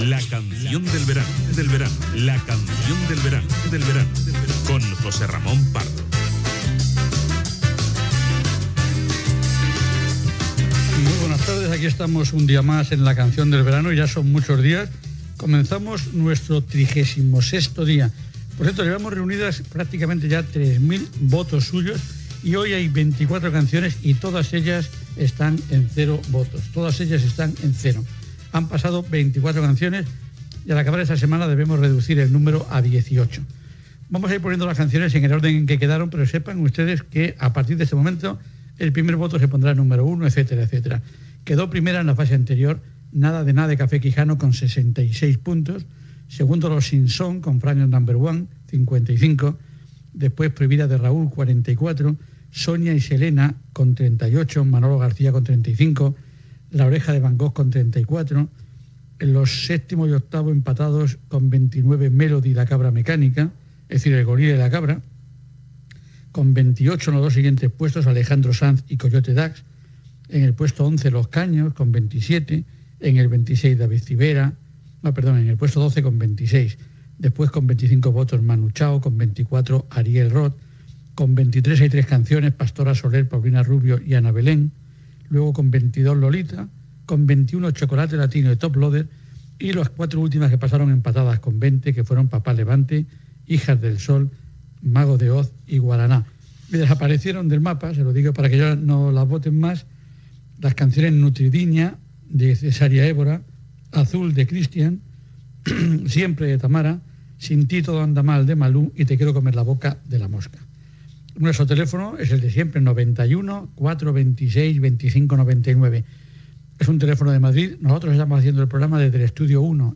Inici de la 36 edició del programa i repàs a les votacions dels oients. Trucades dels oients.
Musical